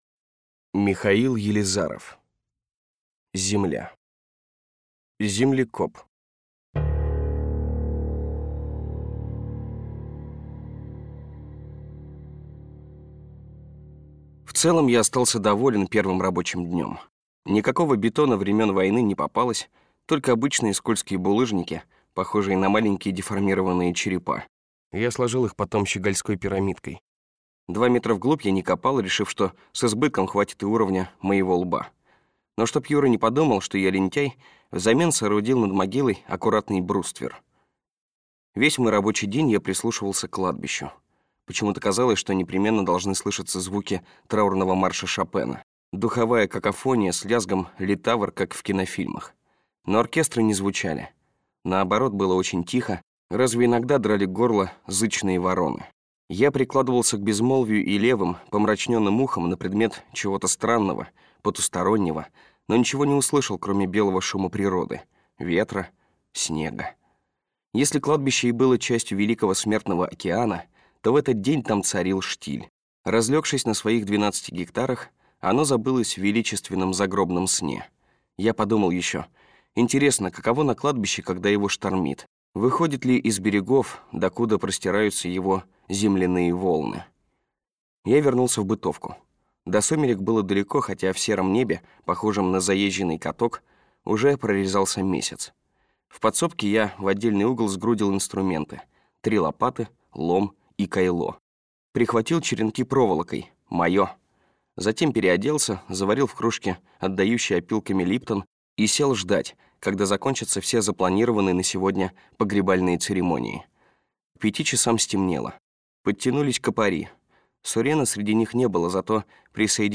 Аудиокнига Земля. Часть 2 | Библиотека аудиокниг